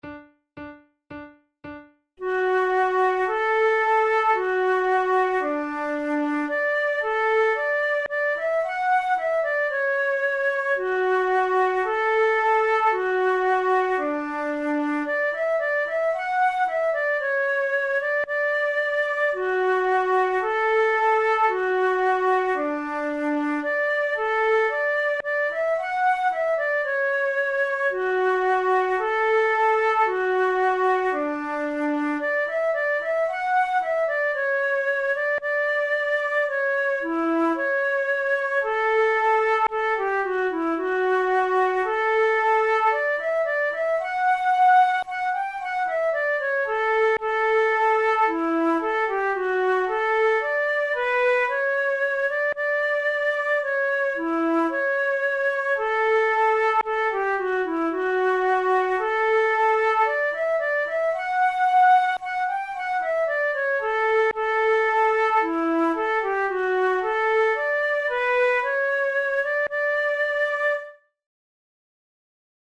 by Jeremiah Clarke, arranged for two Flutes
Originally composed for harpsichord, the majestic “King William's March” comes from the third book of Clarke's Harpsichord Master, which dates from 1702.
Categories: Baroque Marches Difficulty: intermediate